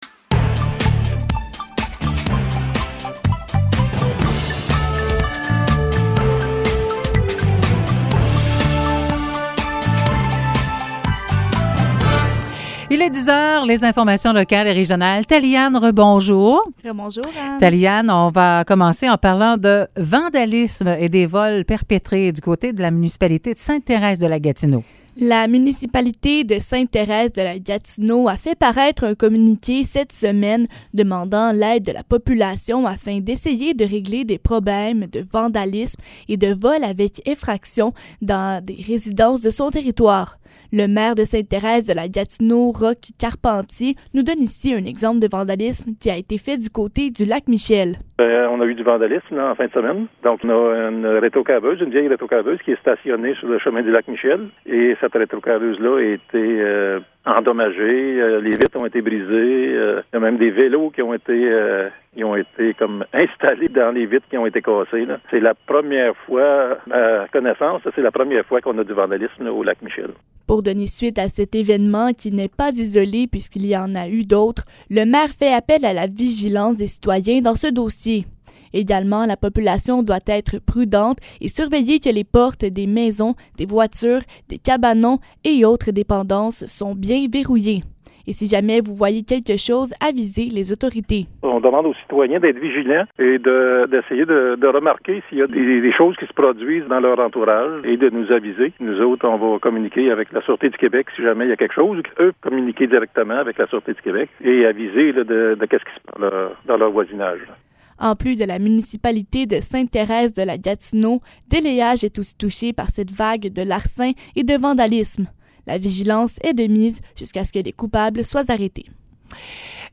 Nouvelles locales - 9 juin 2022 - 10 h